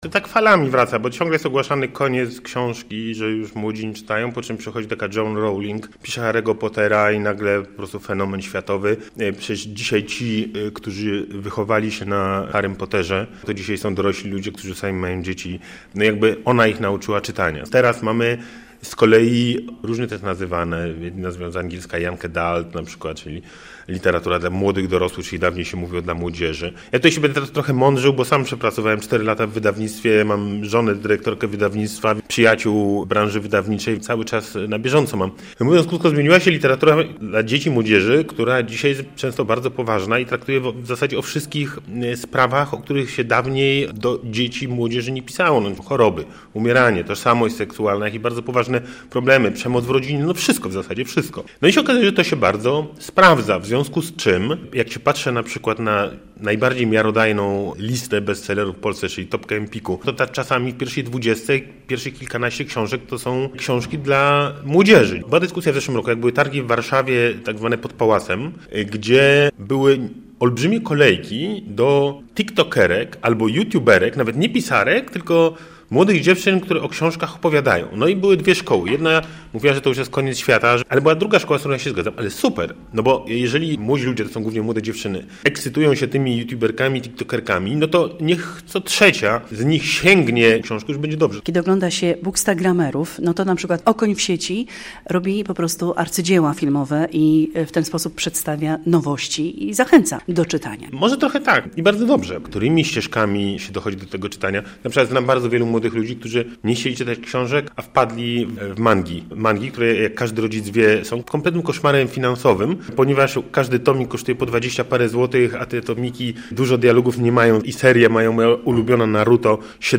Marcin Meller na Targach Książki w Białymstoku - relacja